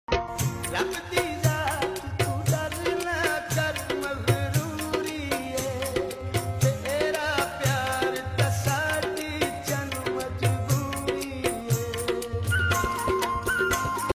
Ai Korean Effect ....... sound effects free download